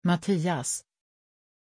Pronunciación de Mattias
pronunciation-mattias-sv.mp3